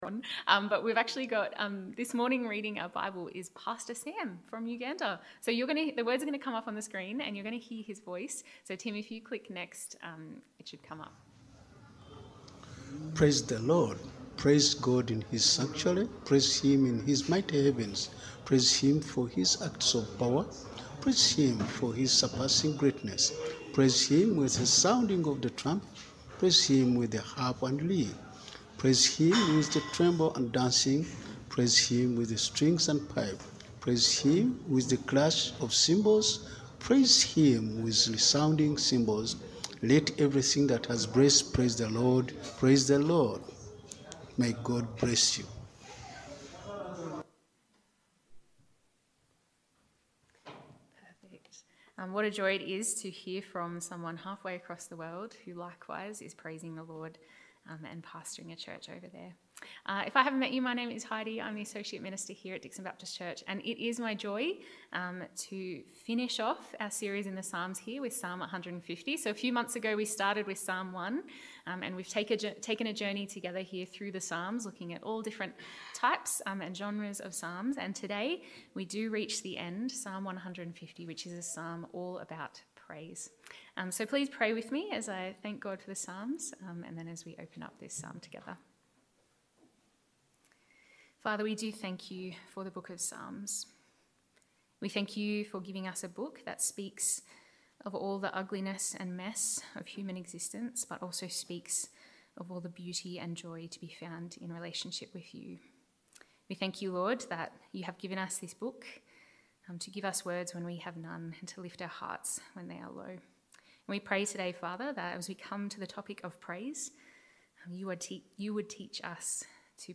Download Download Reference Psalm 150 Psalms Current Sermon Praise the Lord!